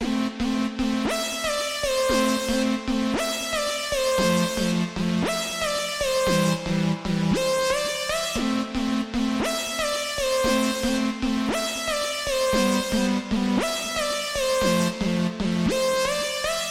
上升恍惚和弦 115 A
描述：轻微的PAD arp，有一个振奋人心的主题，一个法兰盘使其具有金属感。
标签： 115 bpm Trance Loops Pad Loops 2.81 MB wav Key : A
声道立体声